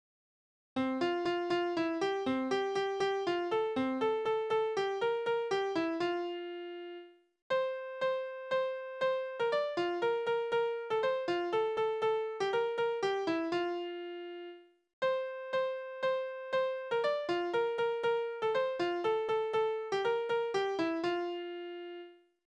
Kindertänze: Lustiger Tanz
Tonart: F-Dur
Taktart: 3/4
Tonumfang: kleine Septime